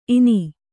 ♪ ini